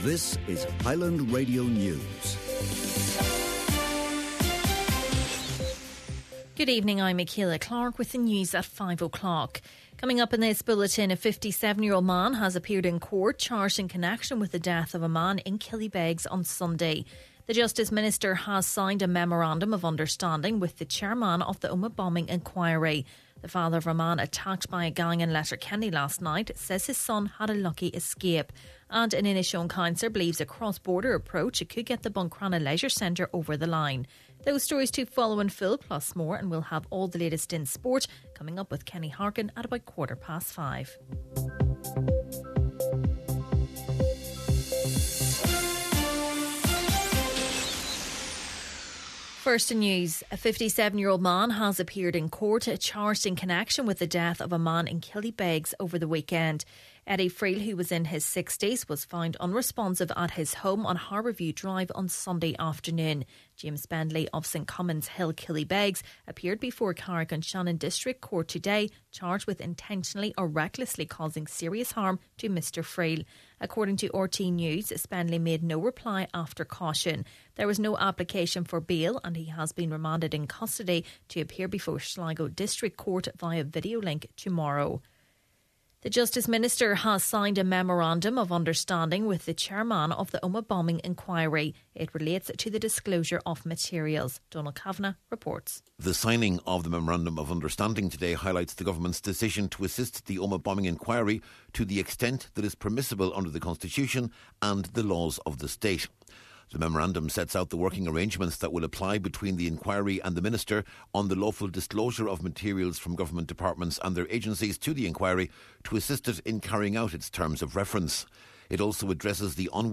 Main Evening News, Sport and Obituaries – Tuesday, April 15th